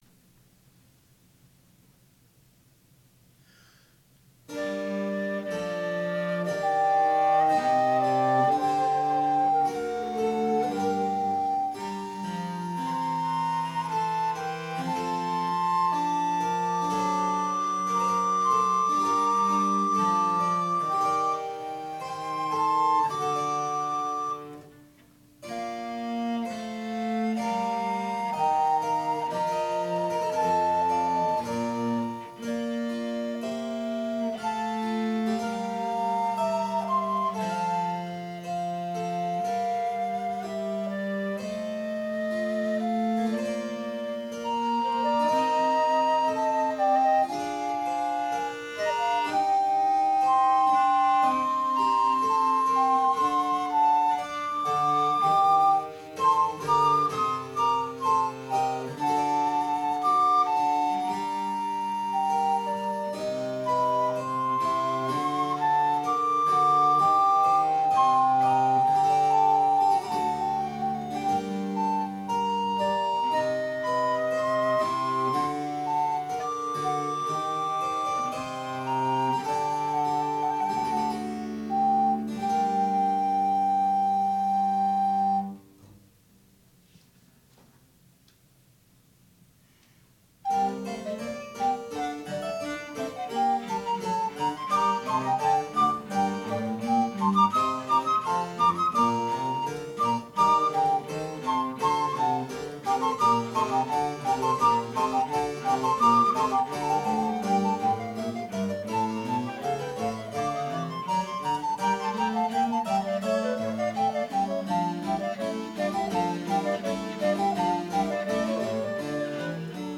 Baroque historical music, viola da gamba, harpsichord, traverso,recorder, Quantz, Telleman, Bach